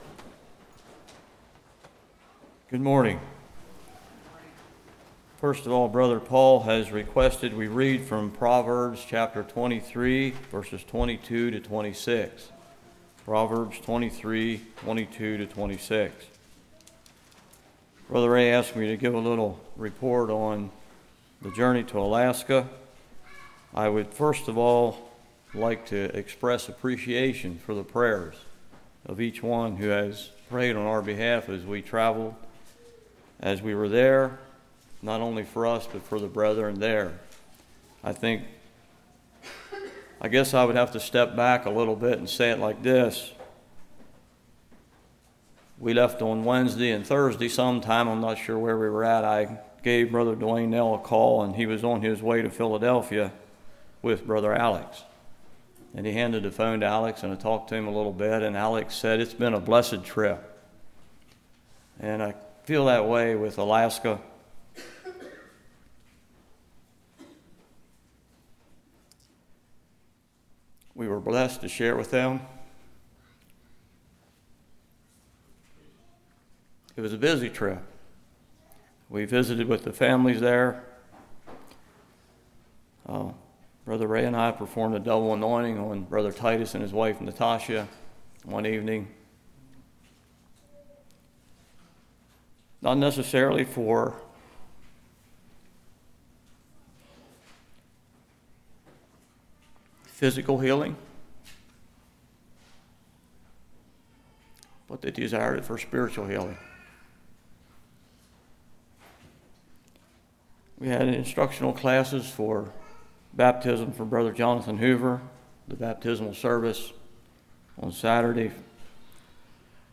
Proverbs 23:22-26 Service Type: Morning What is truth?